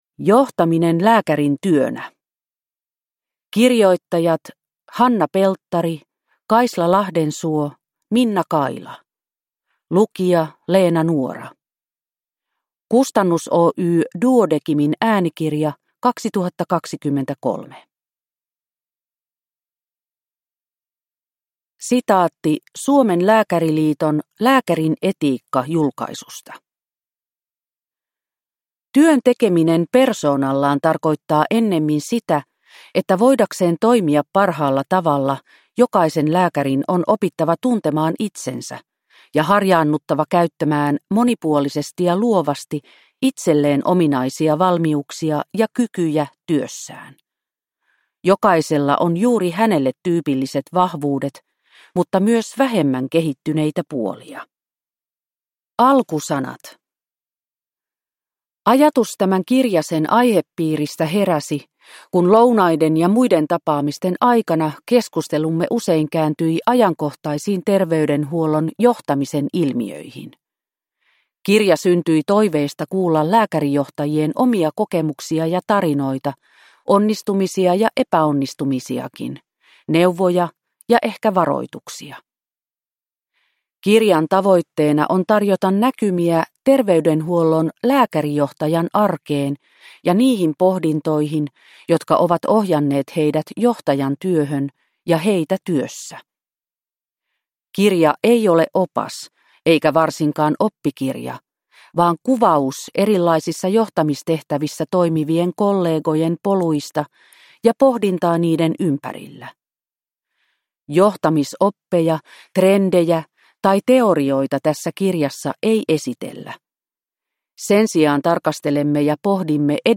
Johtaminen lääkärin työnä – Ljudbok – Laddas ner